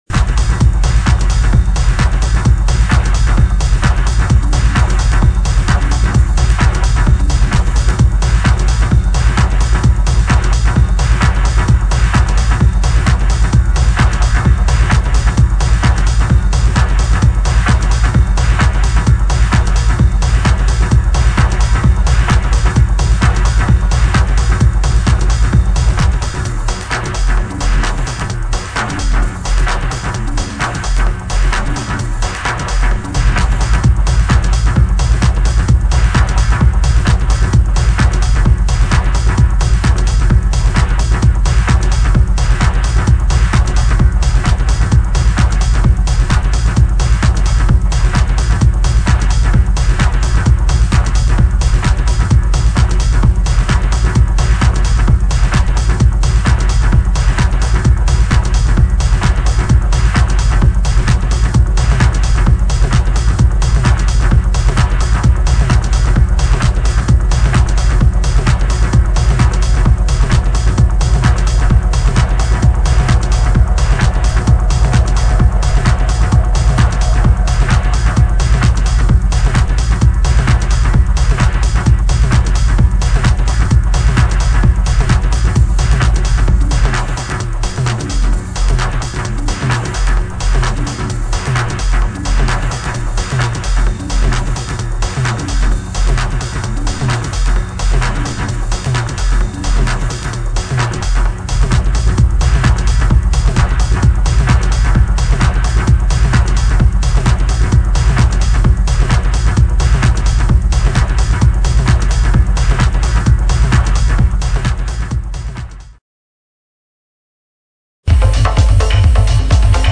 怒涛のドープ・ミニマル！！